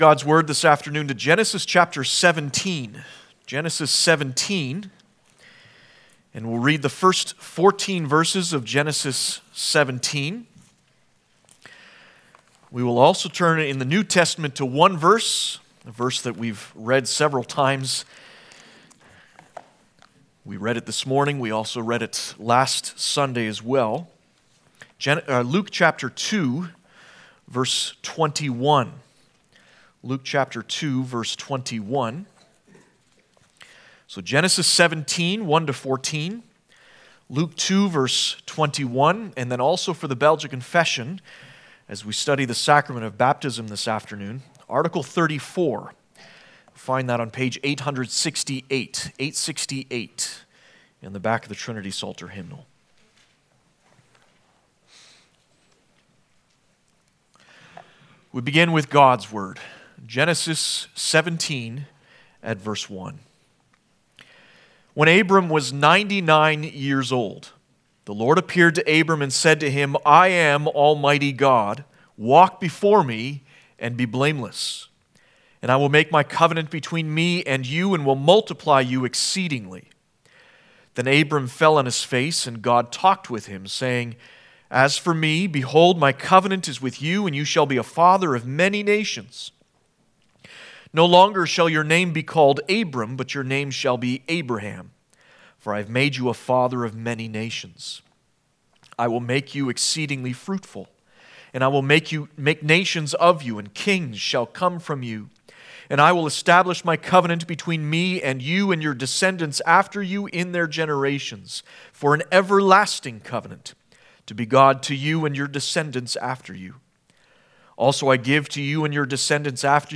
Service Type: Sunday Afternoon